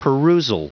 Prononciation du mot perusal en anglais (fichier audio)
Prononciation du mot : perusal